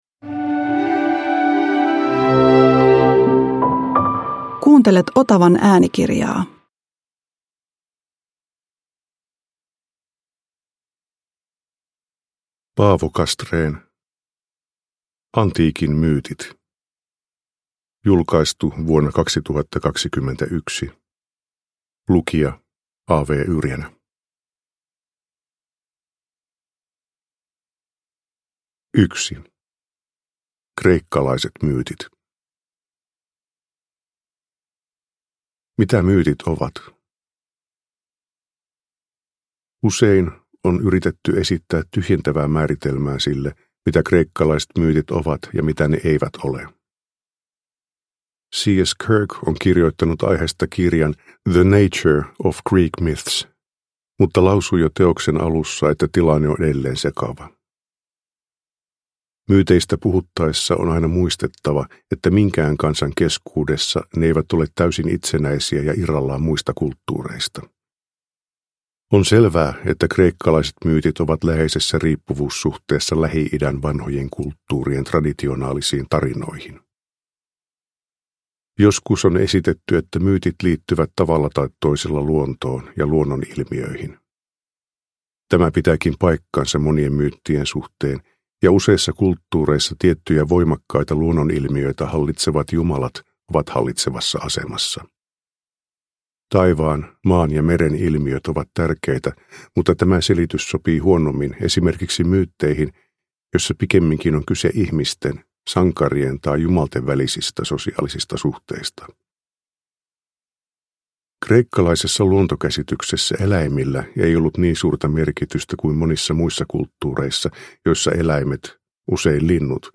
Antiikin myytit – Ljudbok – Laddas ner
Uppläsare: A. W. Yrjänä